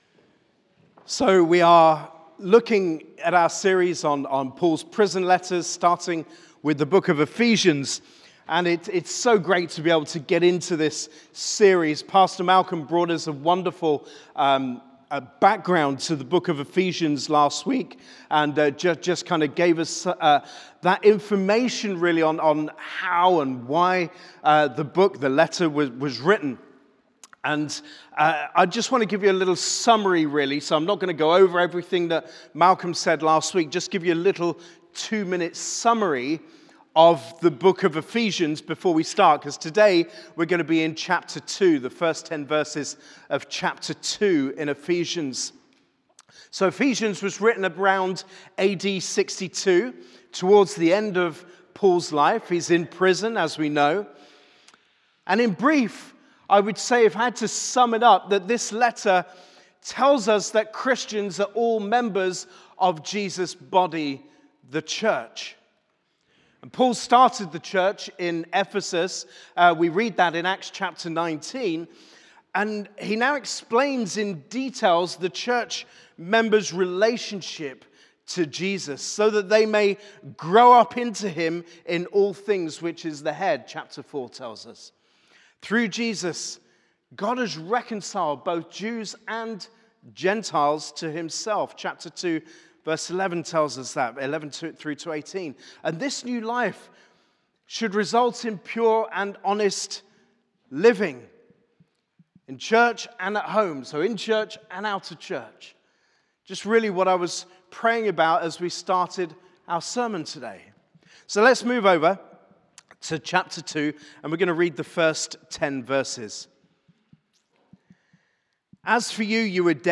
Sermon - Ephesians 2